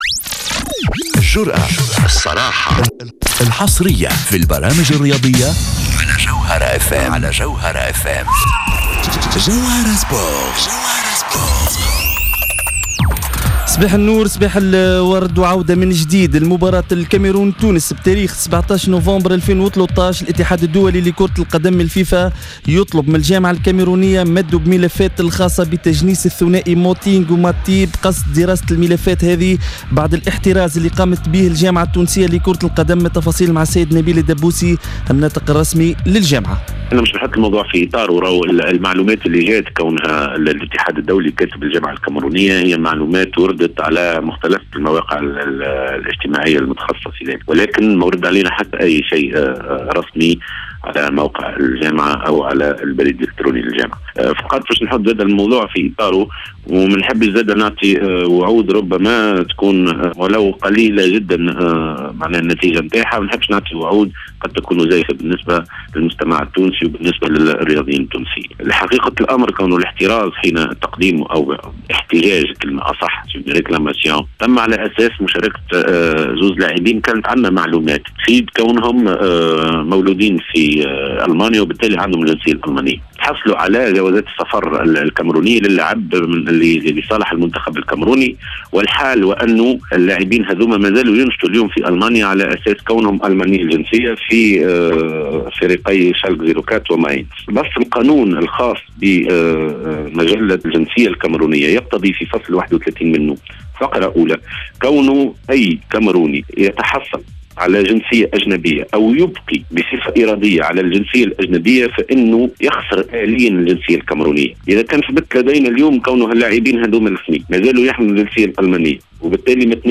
07:30 آخر الأخبار الرياضية ليوم 26 نوفمبر